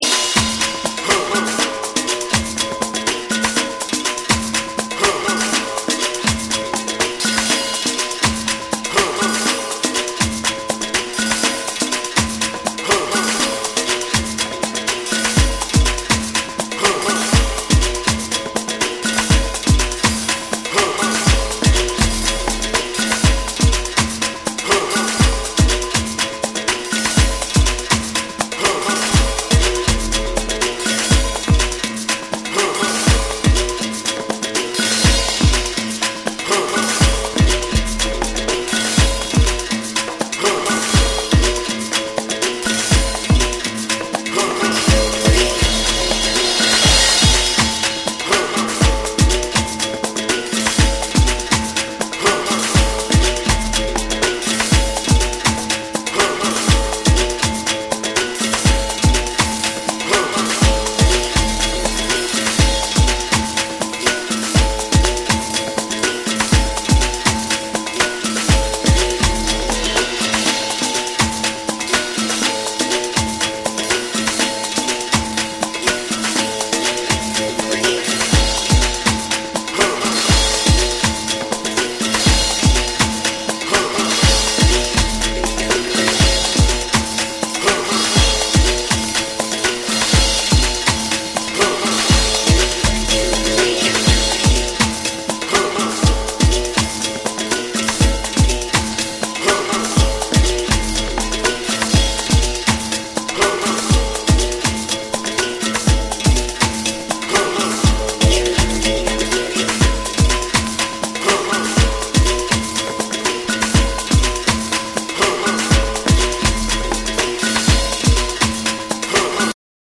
ジャズ、ブルース、ゴスペル、アフロの要素を繊細に織り交ぜた、ソウルフルで美しい壮大なディープ・ハウス作品。
TECHNO & HOUSE / ORGANIC GROOVE